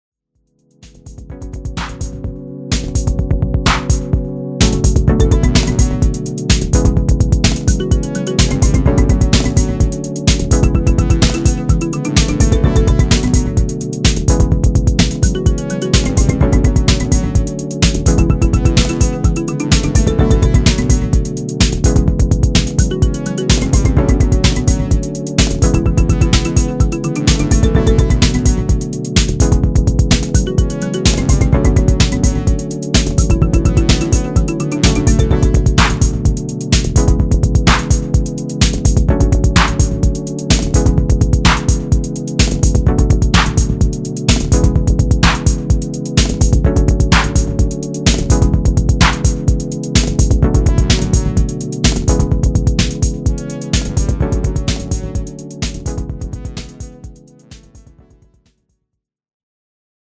a unique analog electronic sound